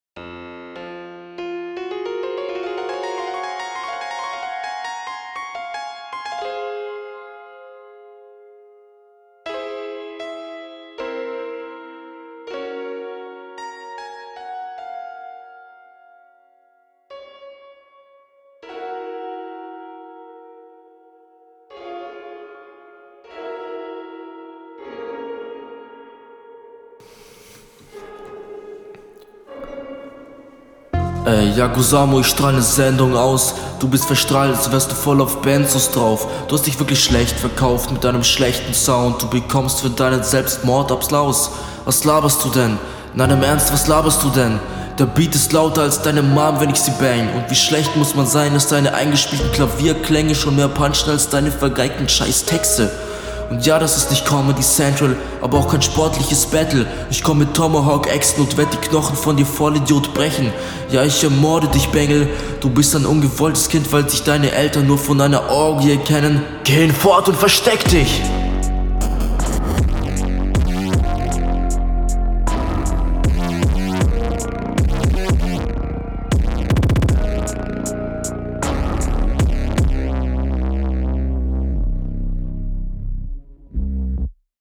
Kommst nicht so schlecht auf den Beat.